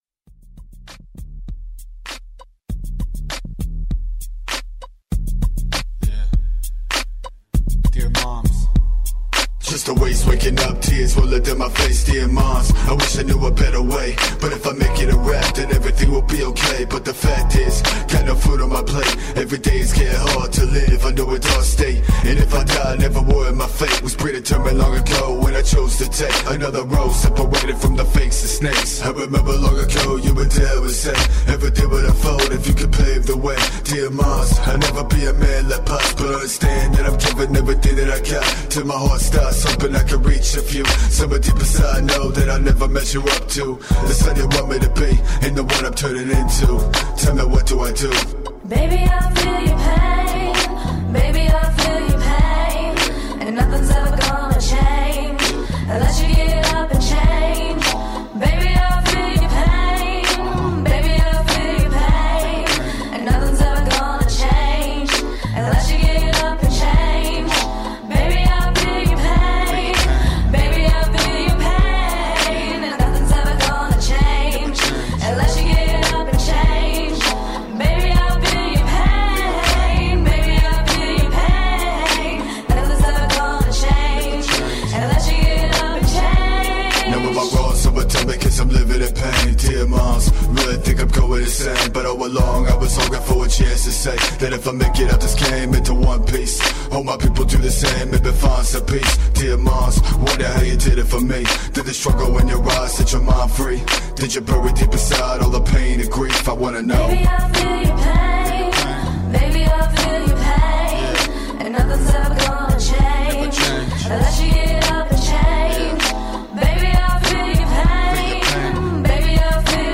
Award winning independent hip hop group.
Tagged as: Hip Hop, Other